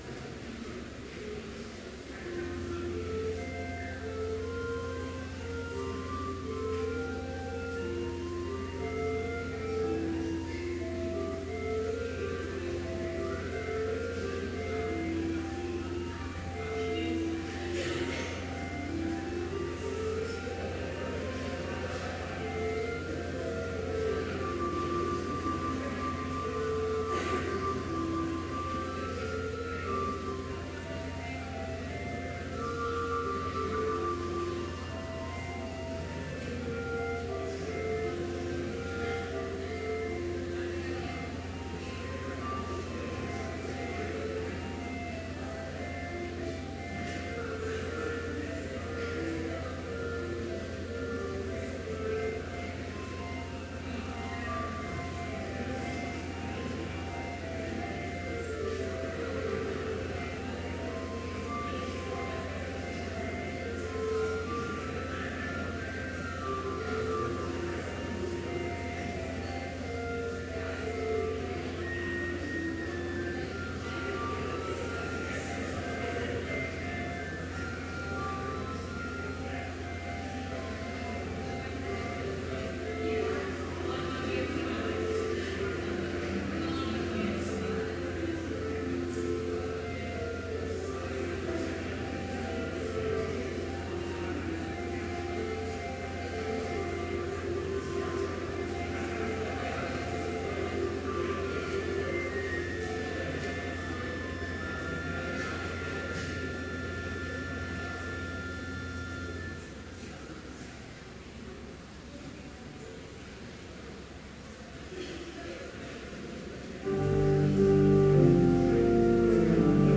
Complete service audio for Chapel - December 15, 2014